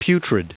Prononciation du mot putrid en anglais (fichier audio)